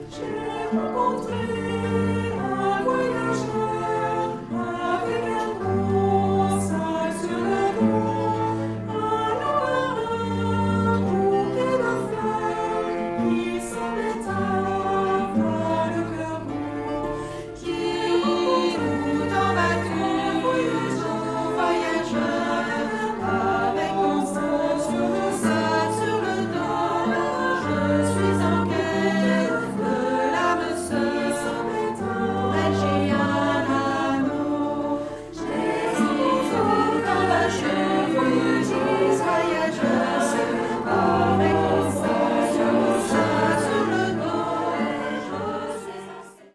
La deuxième phrase mélodique présente quelques subtilités sur lesquelles il sera nécessaire de s’attarder